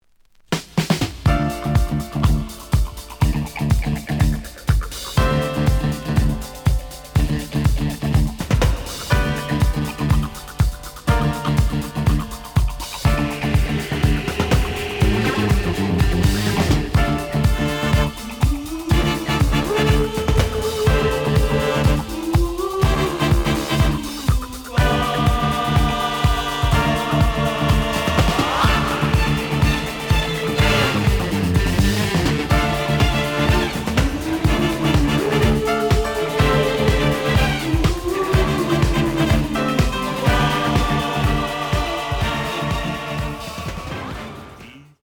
The audio sample is recorded from the actual item.
●Format: 7 inch
●Genre: Disco
Slight edge warp.